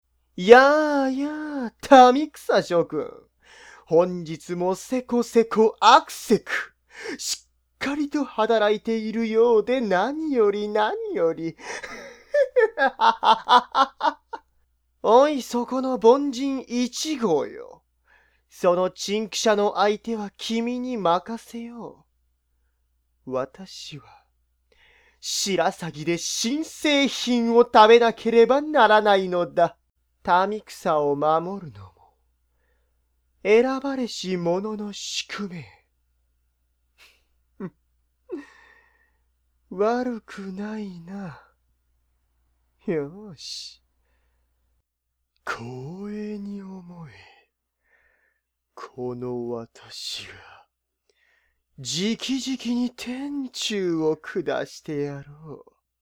演じていただきました！
性別：男性